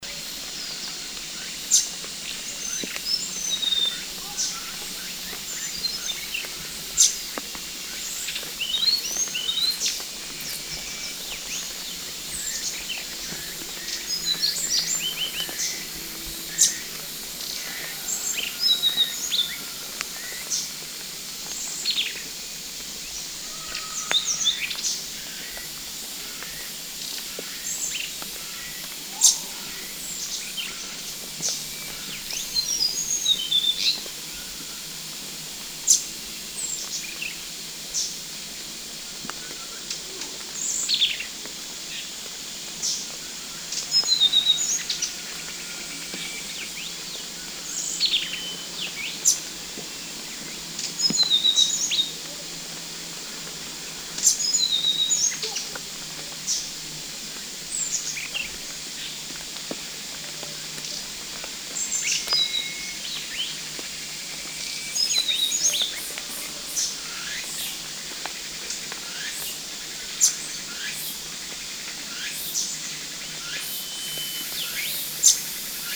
Grive à tête orange ( Zoothera citrina ) ssp melli
Cris de juvéniles non émancipés enregistrés le 14 juillet 2012, en Chine, province du Guangxi, à Shizi Shan près de la ville de Beishan.